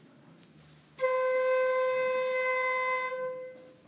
Na realidade ela nos mostra que este segmento de aúdio é composto por várias notas 6. Esta gravação apresenta algo de diferente das outras: o som não é sintético, é “real” e apresenta ruído de aquisição (ruído de discretização, ruído dos mecanismos da flauta, ruído do ambiente de gravação …).